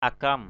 /a-kʌm/ 1. (d.) cây củ chi = échalotte. 2. (d.) củ nén = noix vomique.